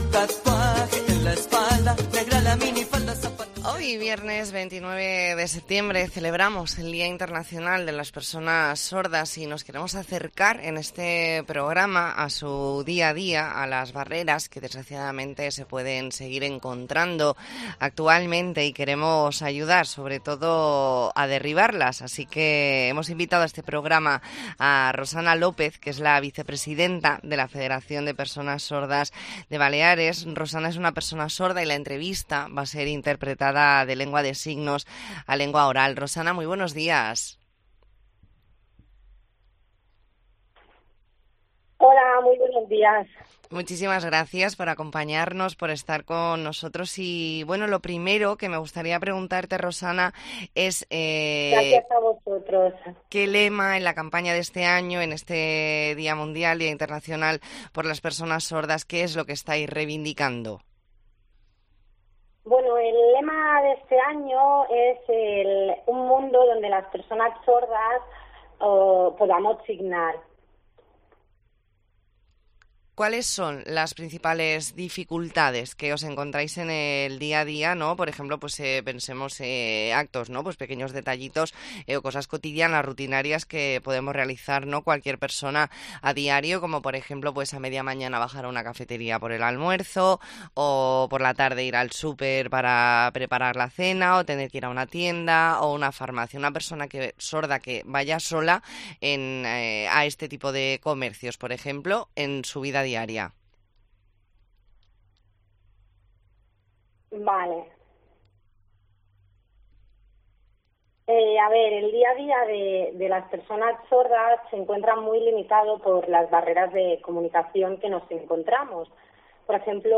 ntrevista en La Mañana en COPE Más Mallorca, viernes 29 de septiembre de 2023.